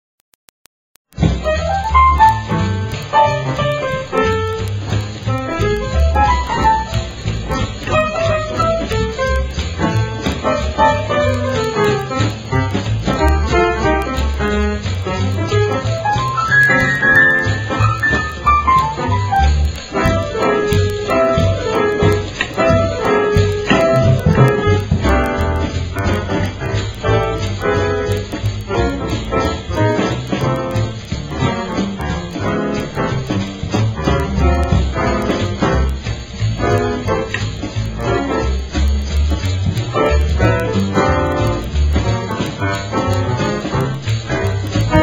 NOTE: Background Tracks 11 Thru 20